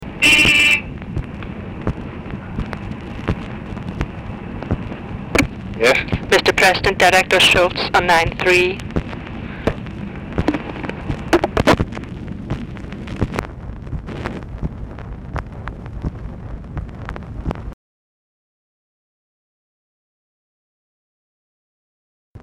Format Dictation belt
Oval Office or unknown location
Specific Item Type Telephone conversation